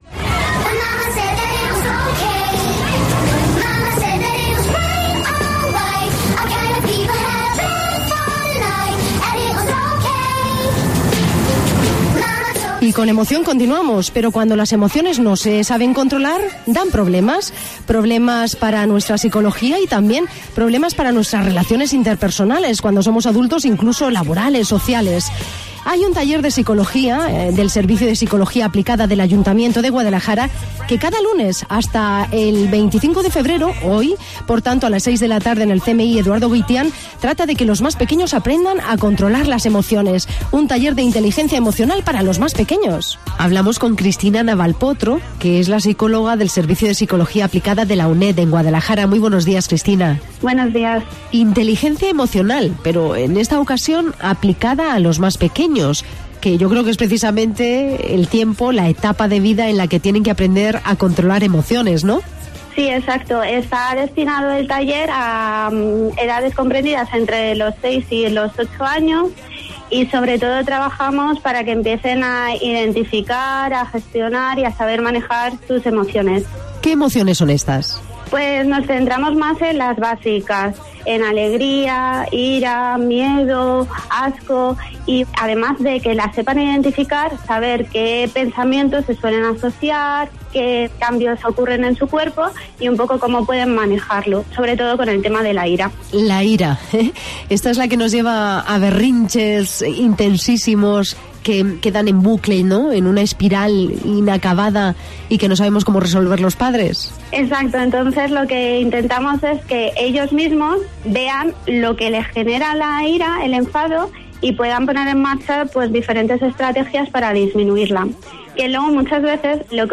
En Cope Guadalajara, hemos charlado con